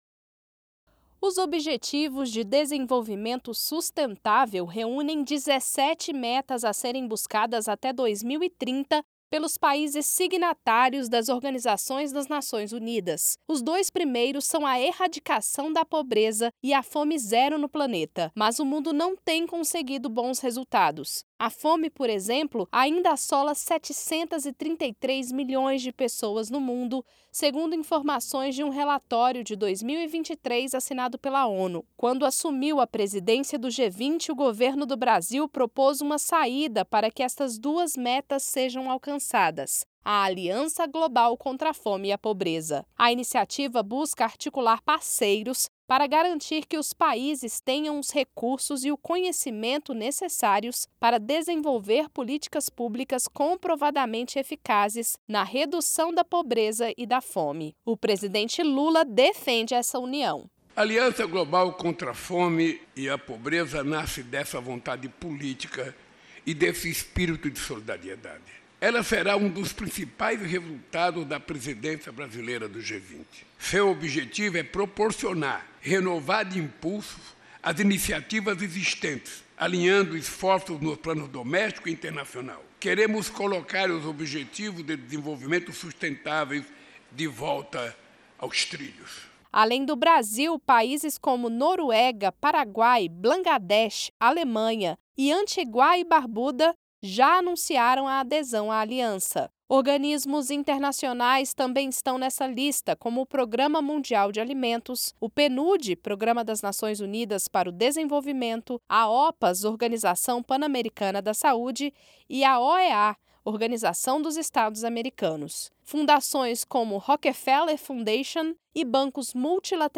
Em situações de emergência e calamidade pública, principalmente as motivadas pela crise climática, a segurança alimentar das populações atingidas é bastante afetada. Neste episódio, a secretária Nacional de Segurança Alimentar e Nutricional, Lilian Rahal, explica como funciona a compra, transporte e doação de cestas de alimentos pelo MDS nestes casos.